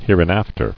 [here·in·af·ter]